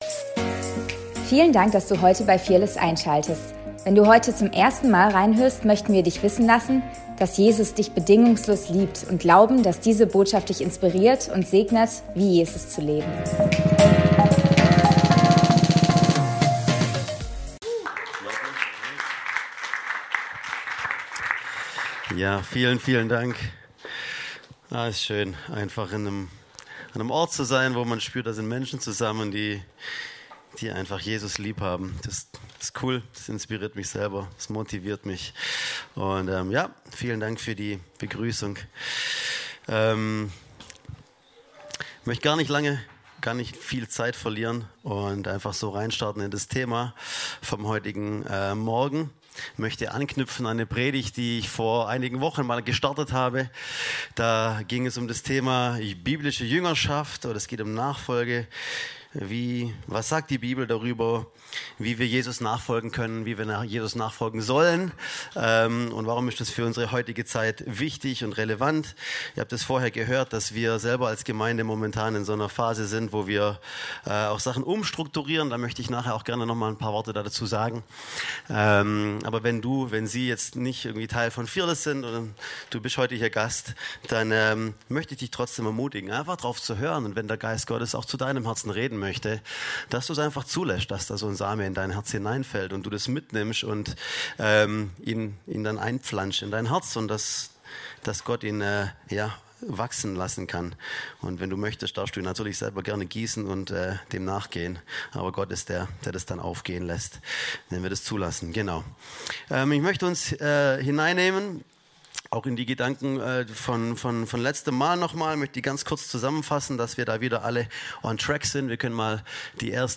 Predigt vom 06.04.2025